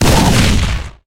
sprout_atk_explo_01.ogg